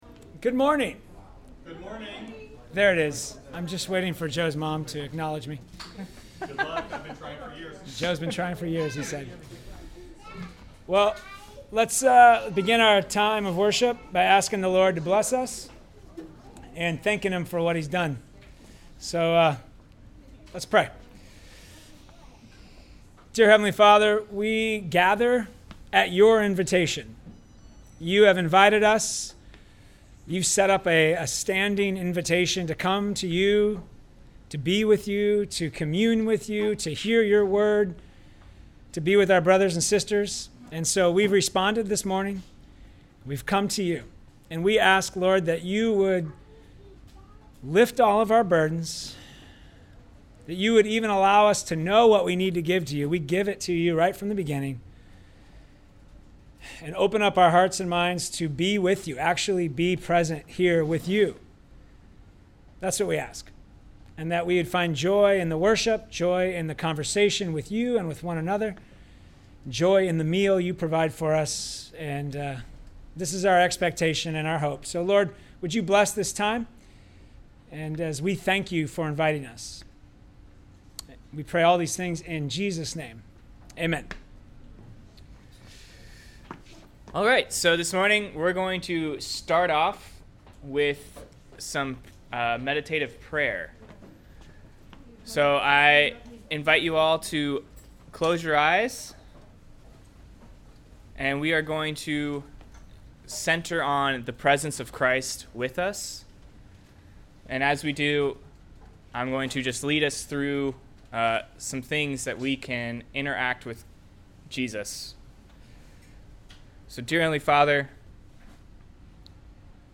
NCCO Sermons